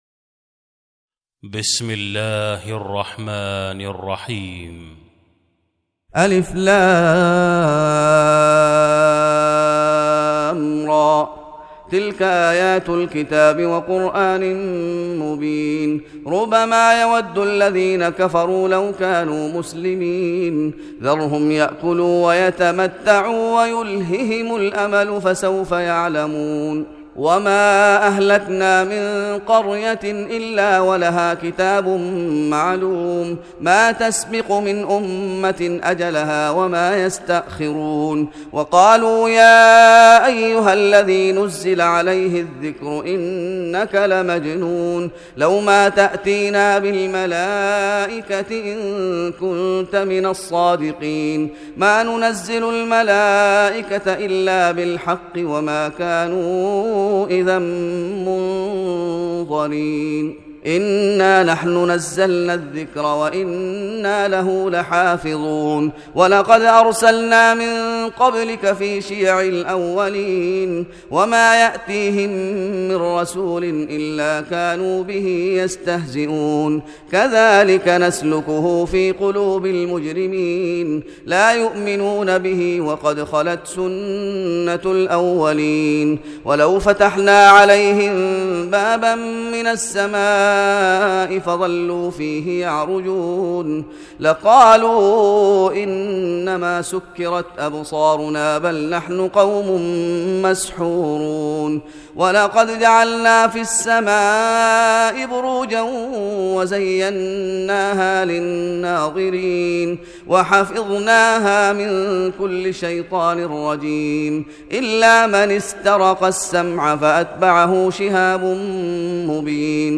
تهجد رمضان 1416هـ سورة الحجر | Tahajjud Ramadan 1416H from Surah Al-Hijr > تراويح الشيخ محمد أيوب بالنبوي 1416 🕌 > التراويح - تلاوات الحرمين